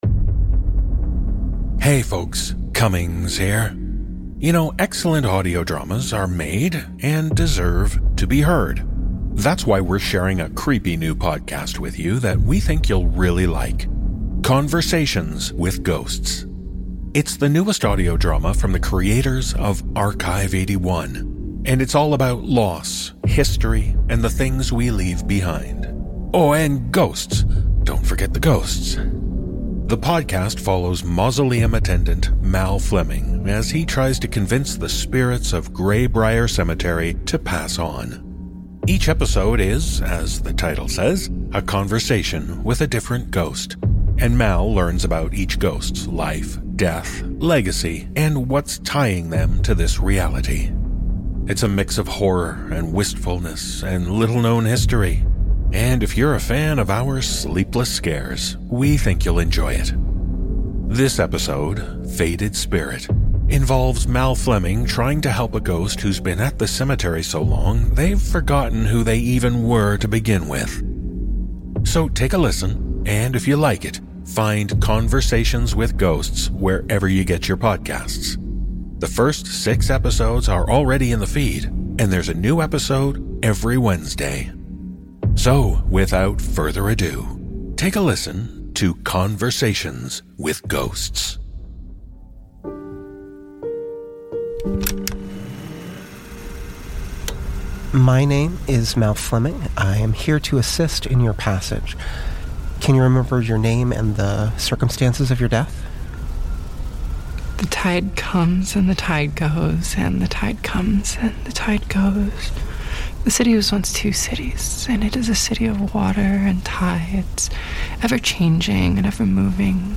It’s the newest audio drama from the creators of Archive 81, The Deep Vault, and Generation Crossing, and it’s all about loss, history, and the things we leave behind. Conversations with Ghosts follows mausoleum attendant Mal Fleming as he tries to convince the spirits of Grey Briar Cemetery to pass on.